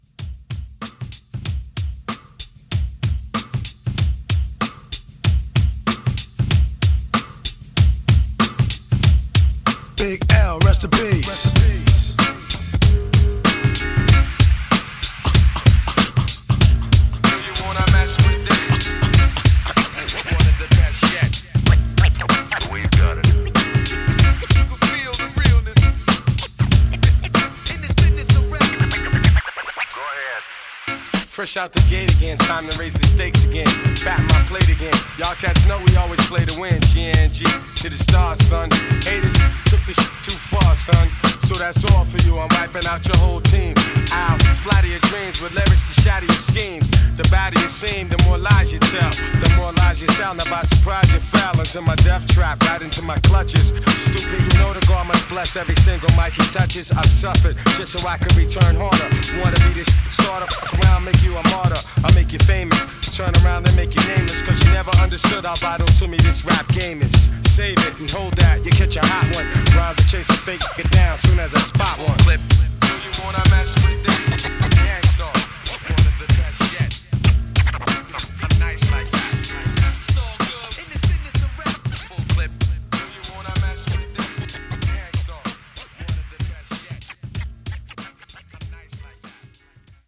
Genres: 90's , OLD SCHOOL HIPHOP , RE-DRUM
Clean BPM: 95 Time